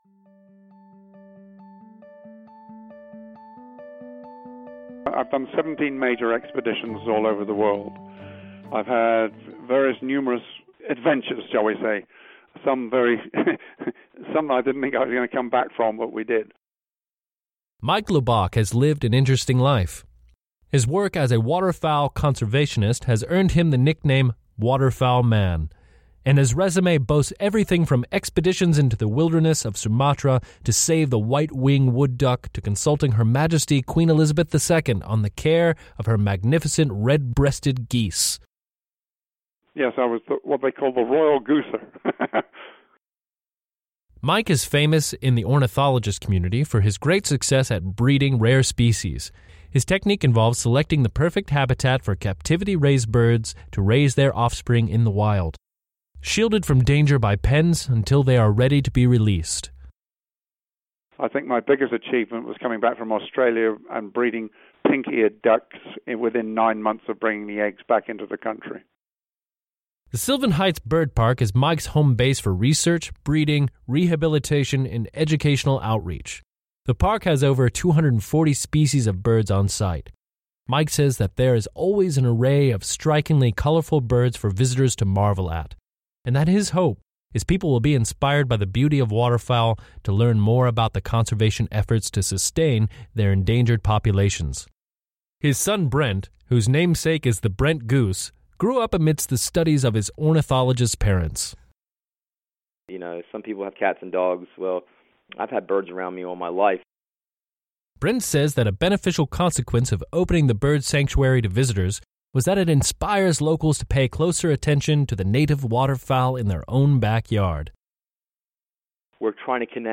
Sylvan Heights - Audio Tour